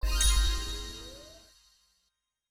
gameClickTrue.mp3